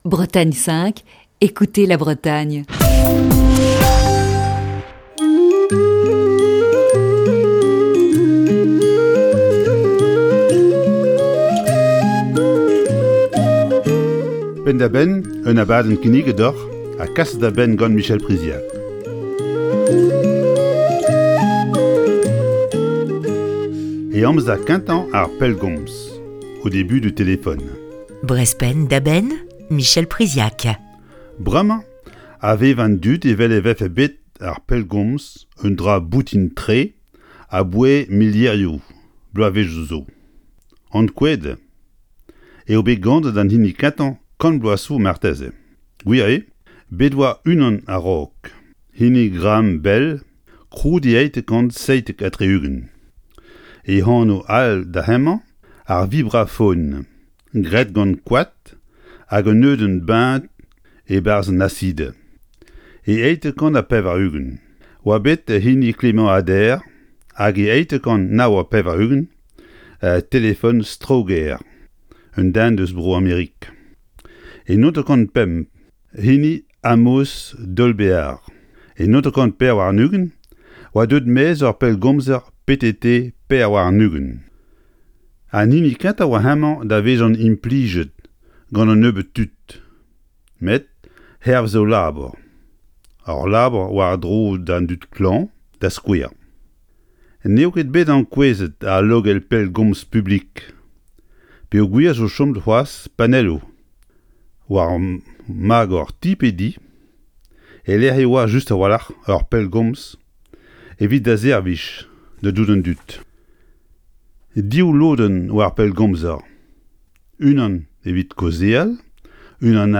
Chronique du 14 juin 2021.